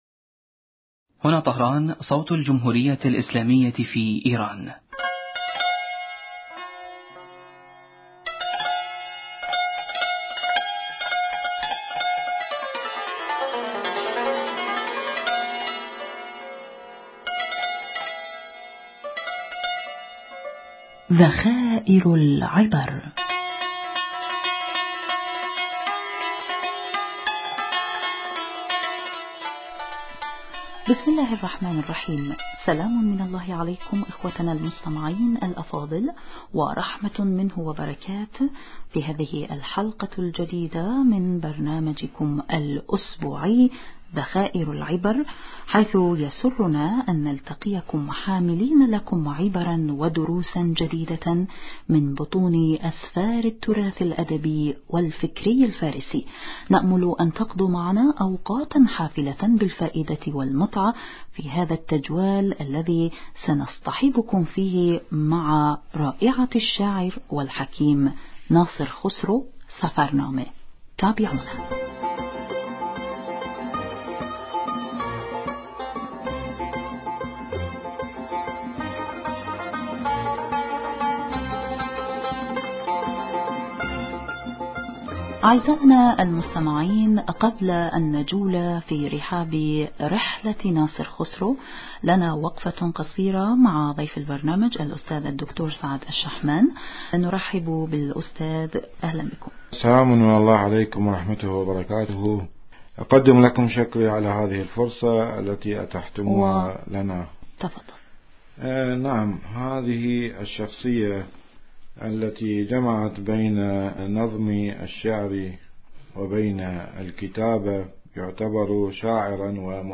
المحاورة: اعزائنا المستمعين قبل ان نجول في رحاب رحلة ناصر خسرو لنا وقفة قصيرة مع ضيف ال